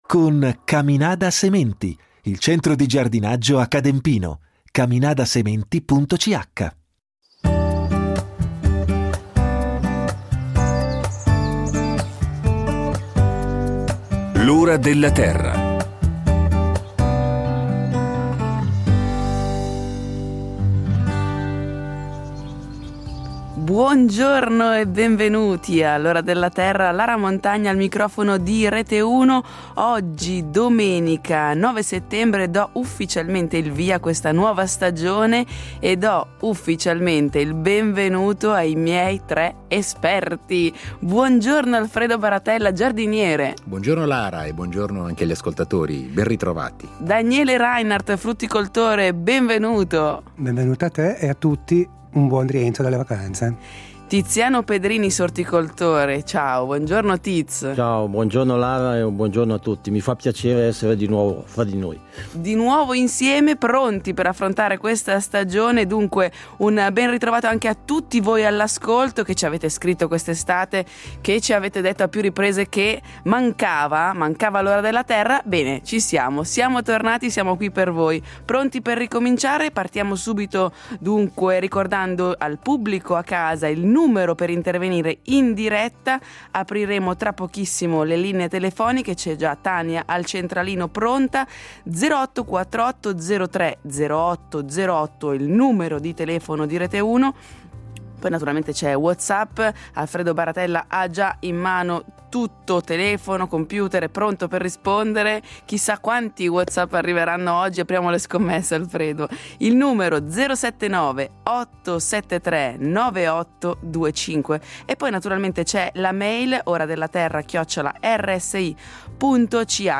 Sarà anche l’occasione per rispondere alle domande del pubblico da casa sulla viticoltura della Svizzera italiana.